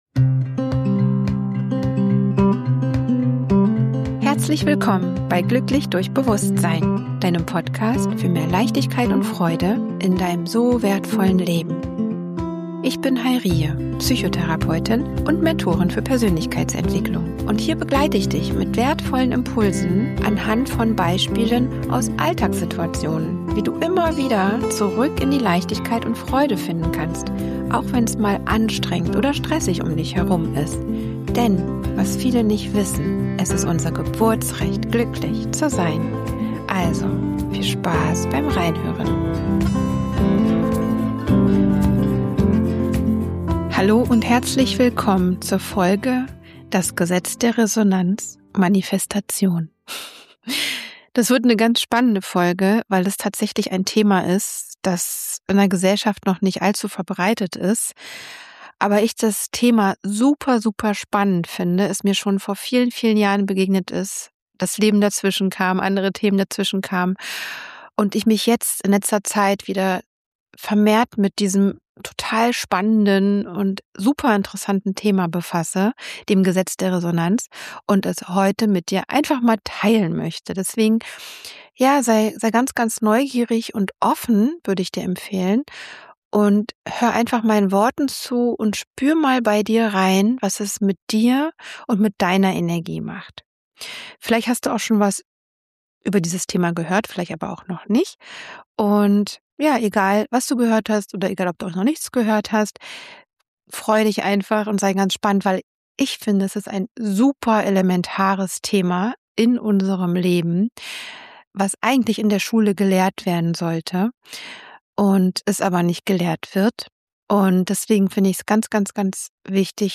Am Ende wartet eine geführte Manifestations-Übung, mit der du direkt ins Spüren und Erleben kommst.